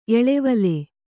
♪ eḷevale